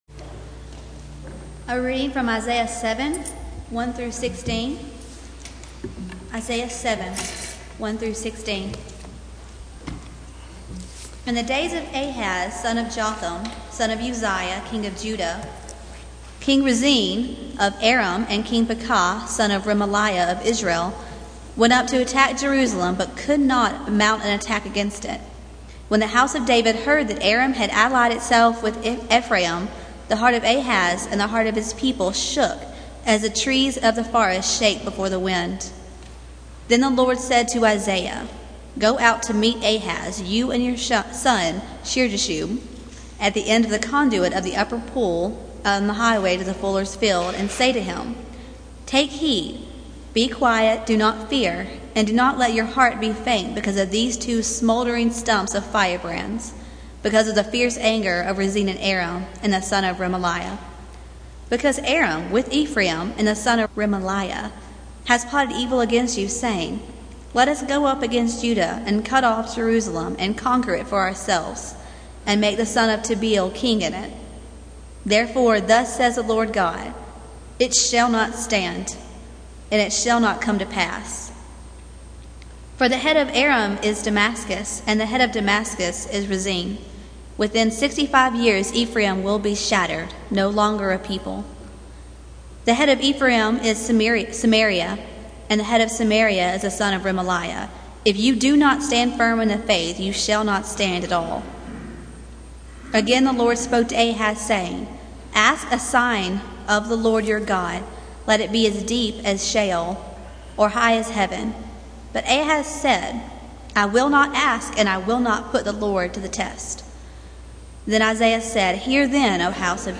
Passage: Isaiah 7:1-11 Service Type: Sunday Morning